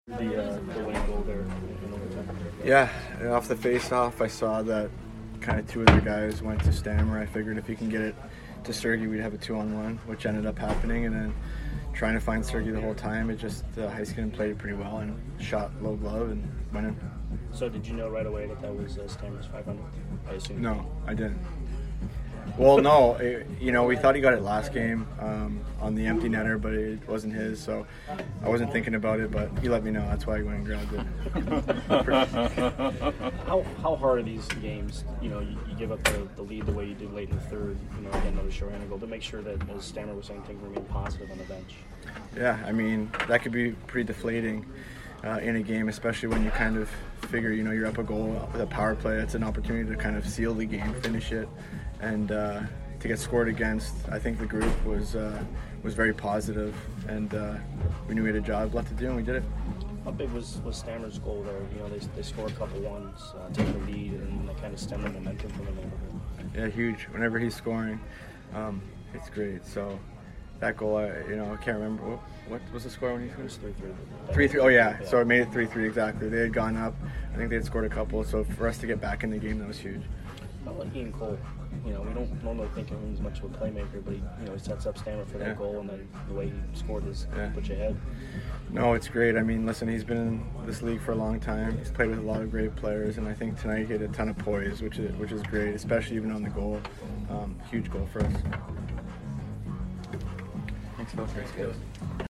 Alex Killorn Post Game 11/15/22 vs DAL